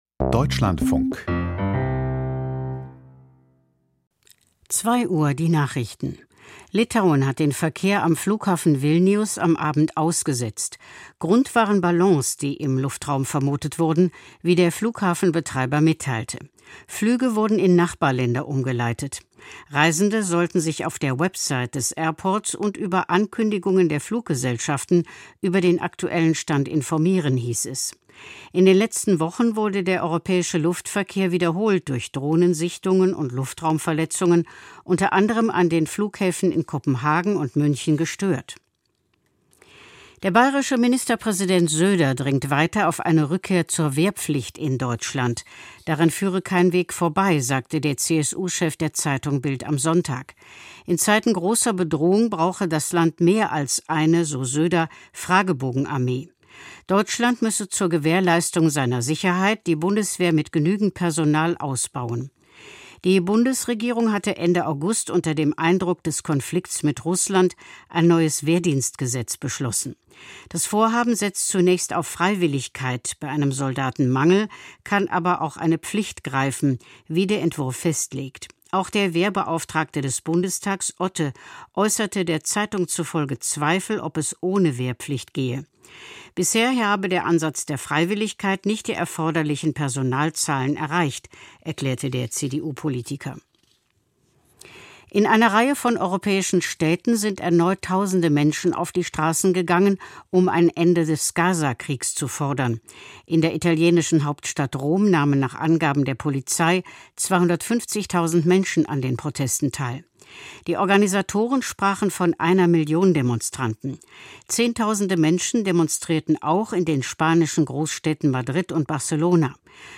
Die Nachrichten vom 05.10.2025, 02:00 Uhr